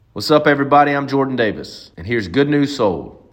LINER Jordan Davis (Good News Sold) 1